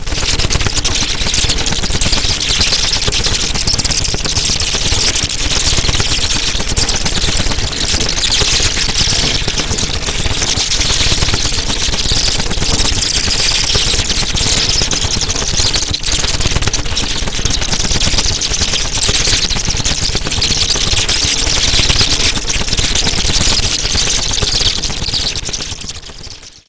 bats_mono.wav